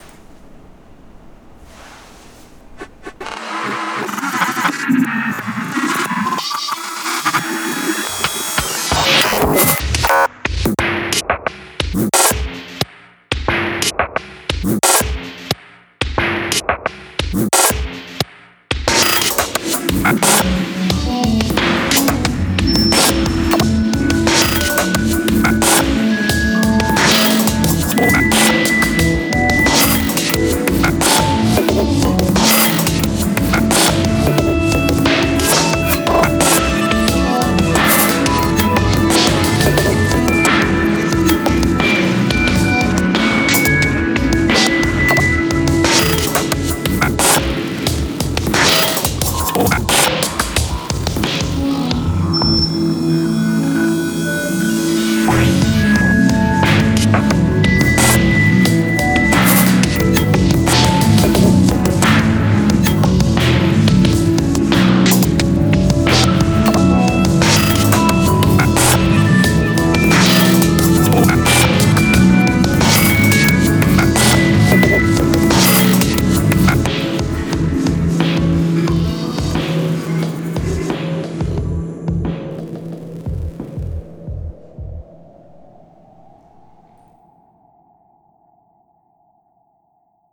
good glitchy thing.